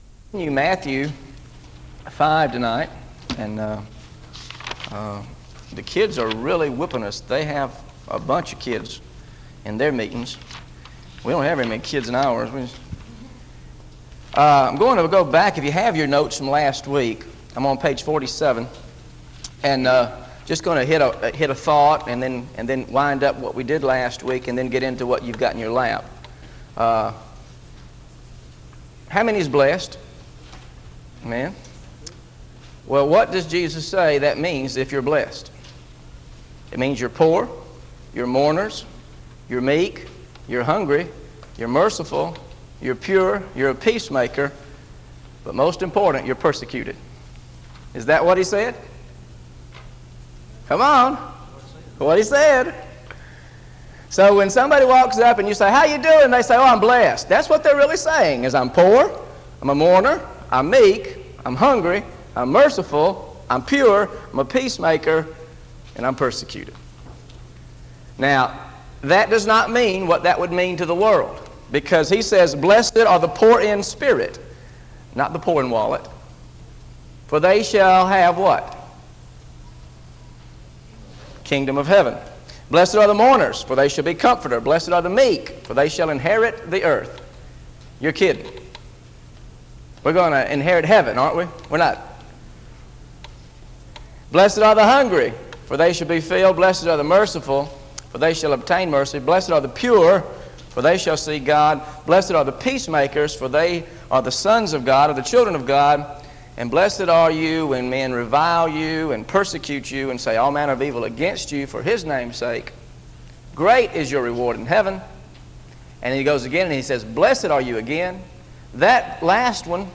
Listen to the Teaching on Matthew 5 Part 2 Audio